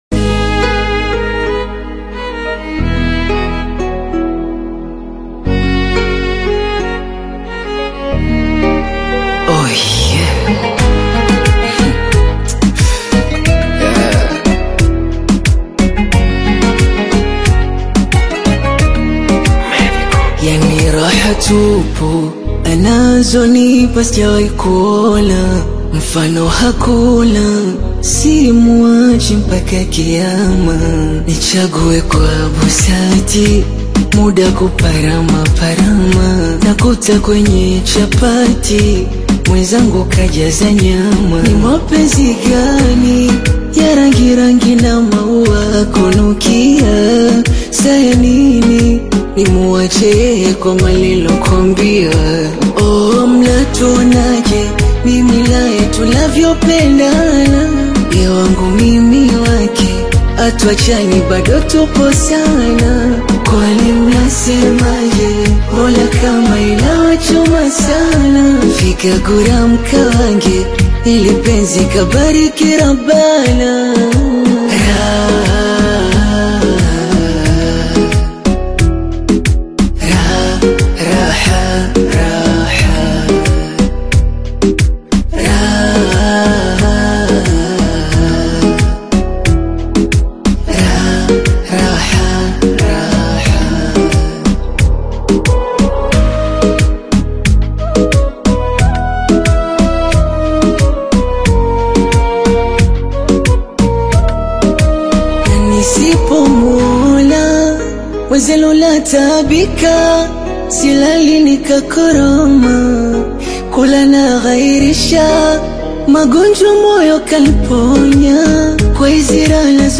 feel-good Afro-Pop release